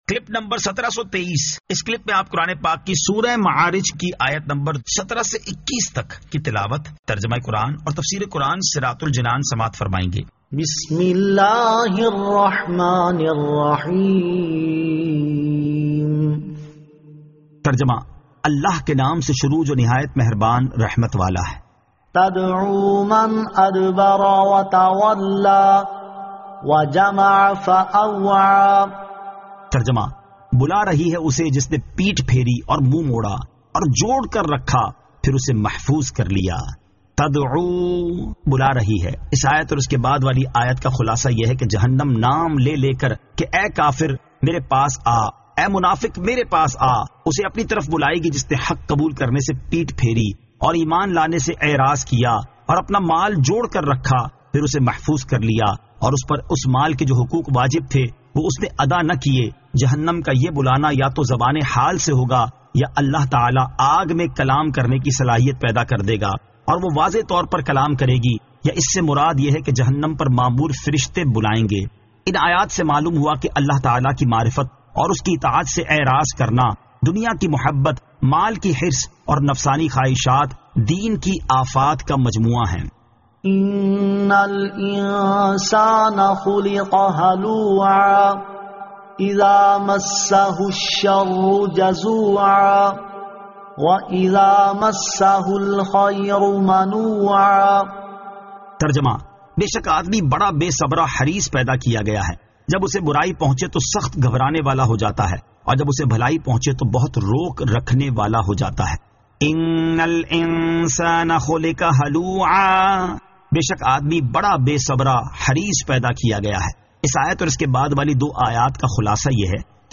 Surah Al-Ma'arij 17 To 21 Tilawat , Tarjama , Tafseer